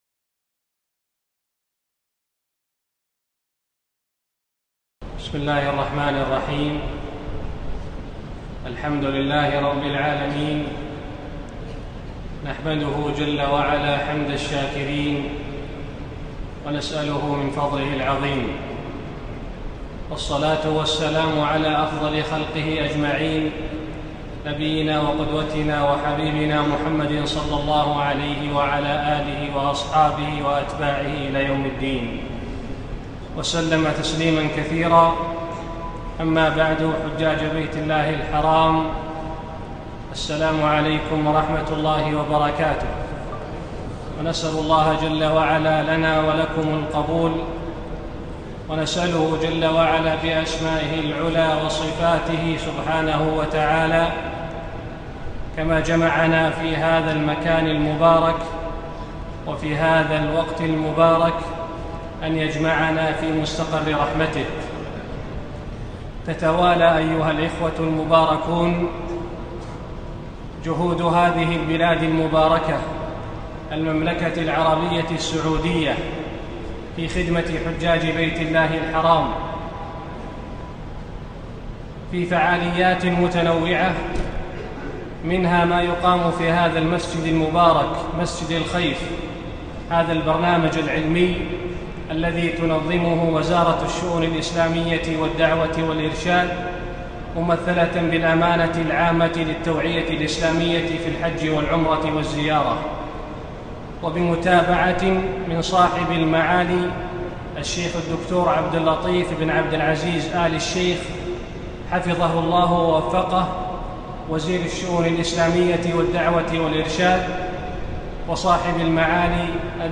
تعظيم شعائر الله - من محاضرات مسجد الخيف 1439